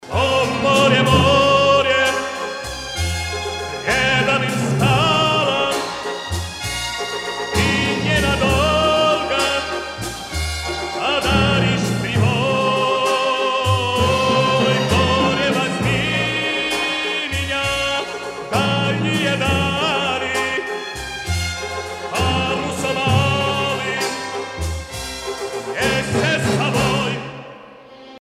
ретро , эстрадные